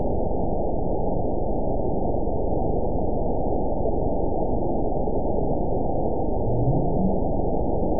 event 922002 date 12/25/24 time 01:02:28 GMT (4 months, 1 week ago) score 9.13 location TSS-AB02 detected by nrw target species NRW annotations +NRW Spectrogram: Frequency (kHz) vs. Time (s) audio not available .wav